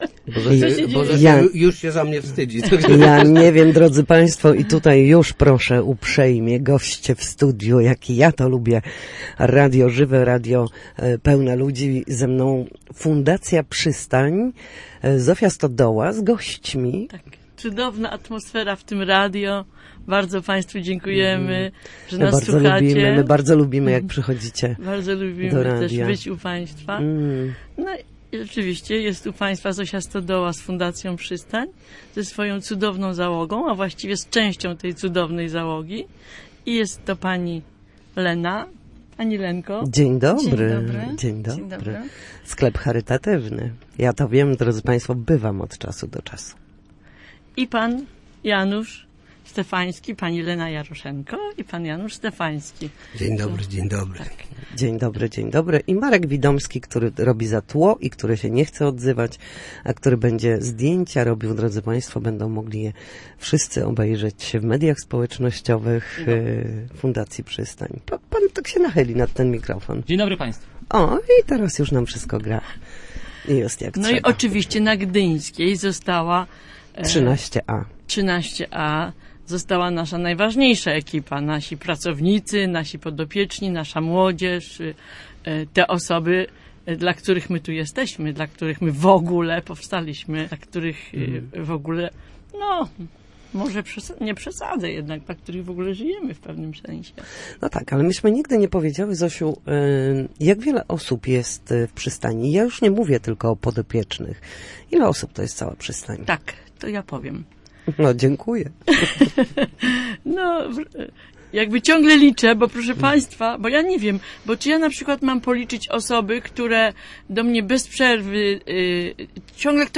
Gośćmi Studia Słupsk byli dziś